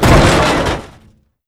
metal_impact.wav